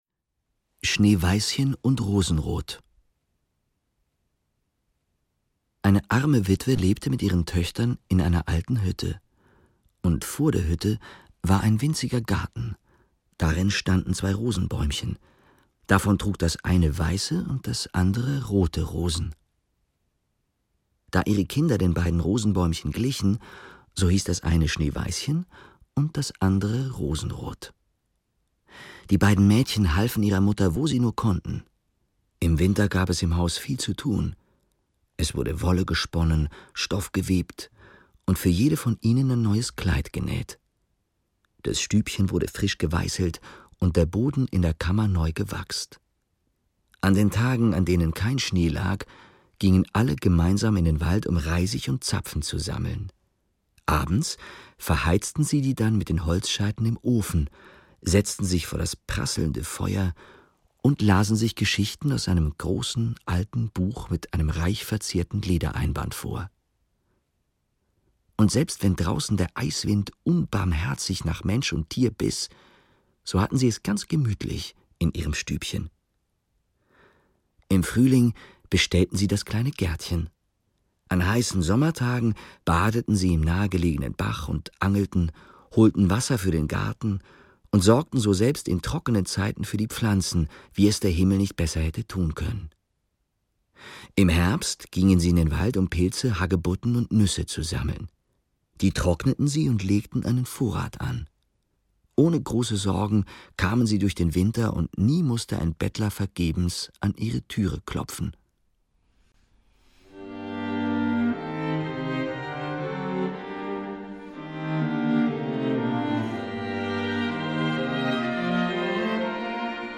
Kontrabass
Erzähler